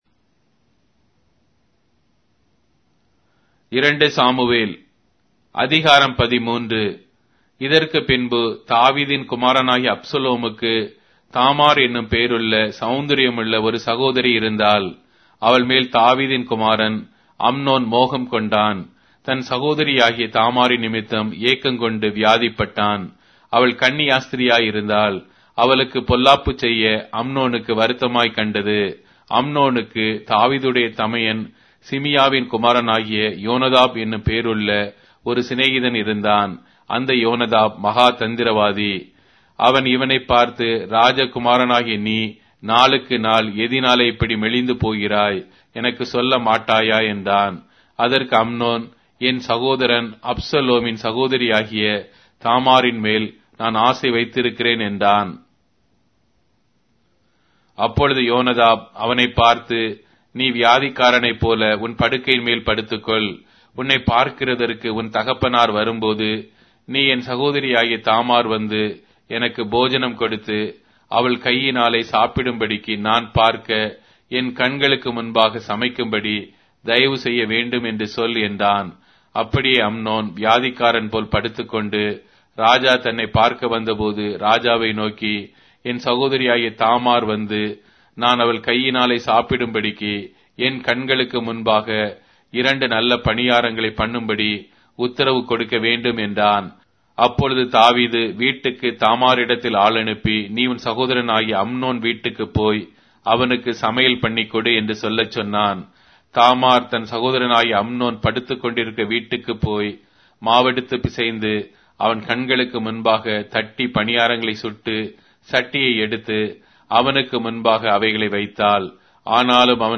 Tamil Audio Bible - 2-Samuel 17 in Ervhi bible version